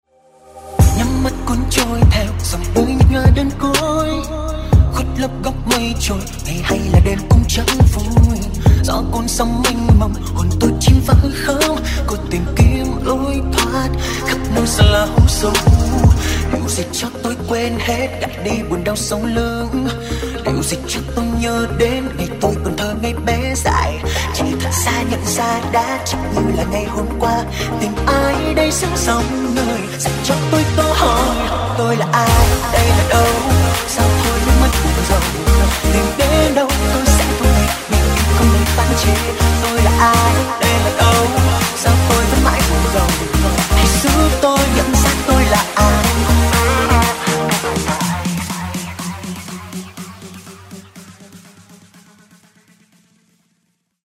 G House Version